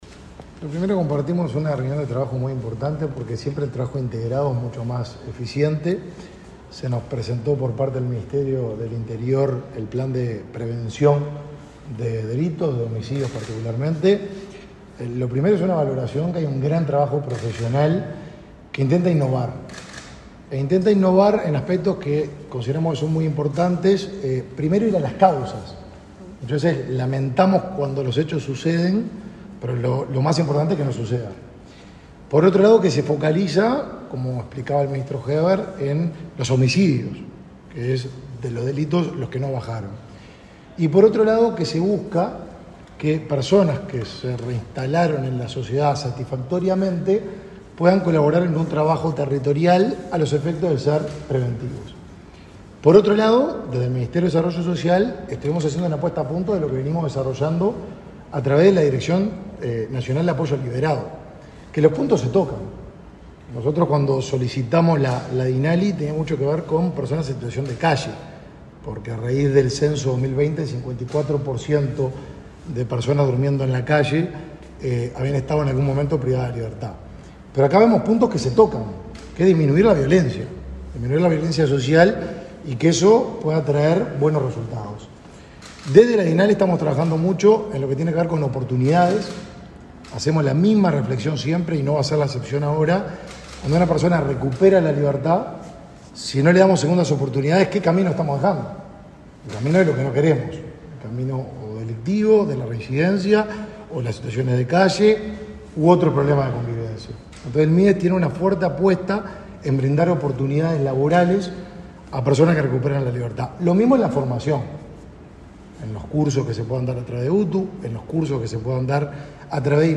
Declaraciones del ministro de Desarrollo Social, Martín Lema
El ministro de Desarrollo Social, Martín Lema, dialogó con la prensa, luego de la reunión que mantuvo con su par del Interior, Luis Alberto Heber,